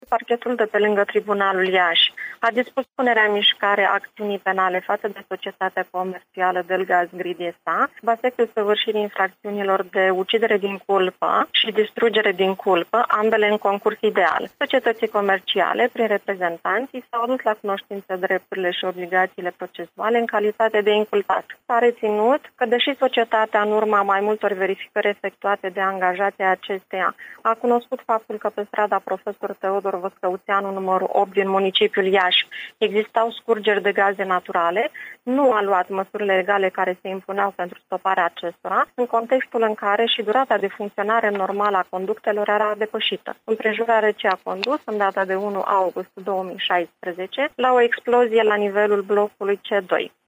Acuzaţiile aduse de procurori firmei de gaz sunt de ucidere din culpă şi distrugere din culpă, după cum a precizat Mihaela Apostol, prim procuror la parchetul de pe lângă Tribunalul Iaşi: Parchetul de pe lângă Tribunalul Iaşi a dispus punerea în mişcare a acţiunii penale faţă de SC Delgaz Grid SA, sub aspectul săvârşirii infracţiunilor de  ucidere din culpă şi distrugere din culpă, ambele în concurs ideal.